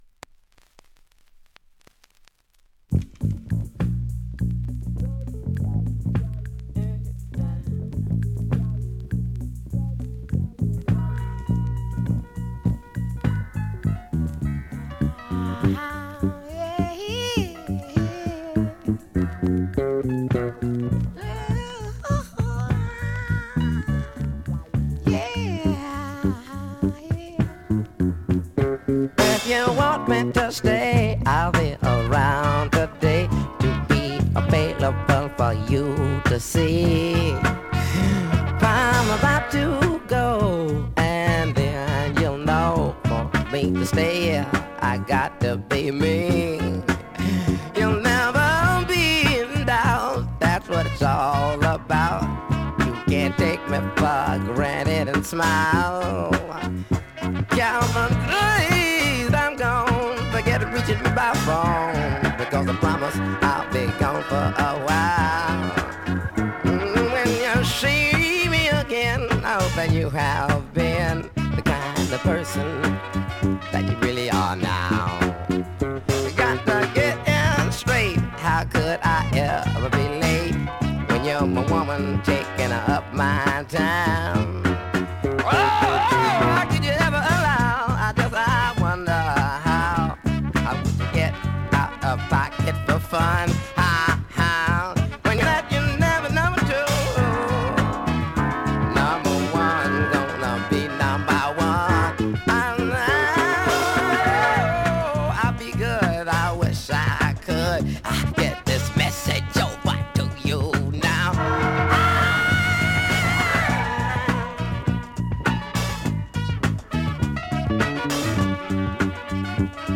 現物の試聴（両面すべて録音時間６分９秒）できます。
Mono
Stereo